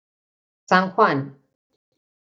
Ausgesprochen als (IPA)
/ˌsan ˈxwan/